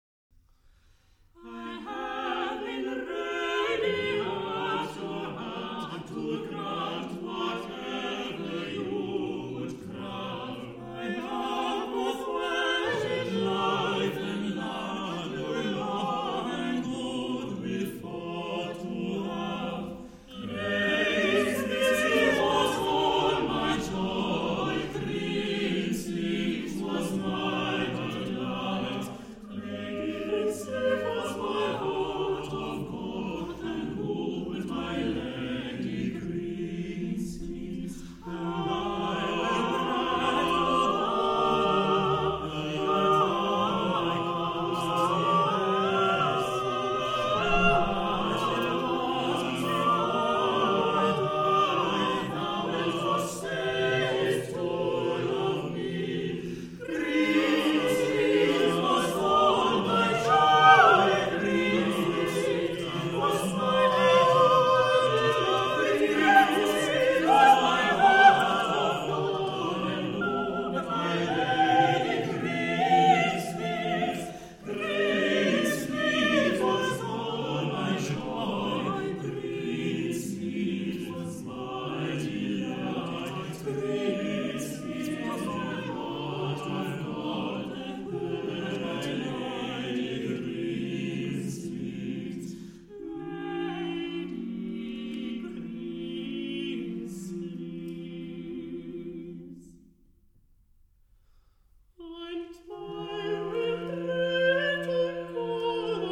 Extrait, Greensleeves – musique traditionnelle (Arrangement Thibaut Louppe) par le Choeur Spirito pour « Heaven ».